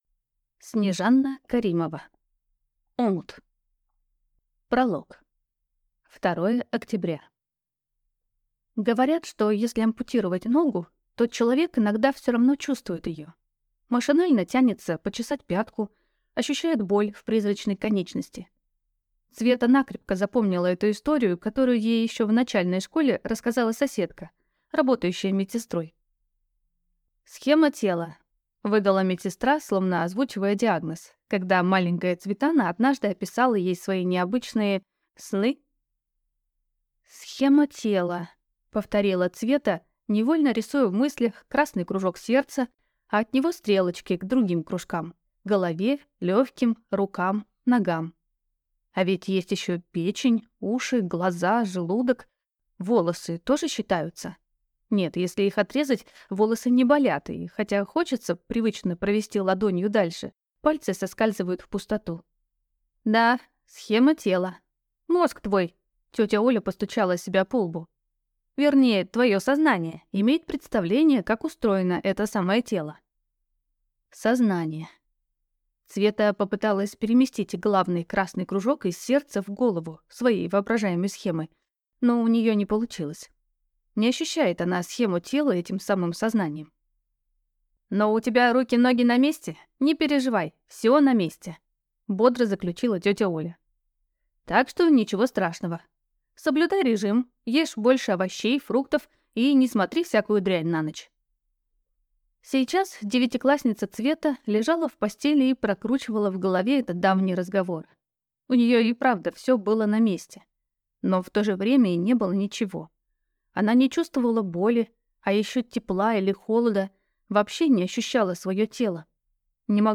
Аудиокнига Омут | Библиотека аудиокниг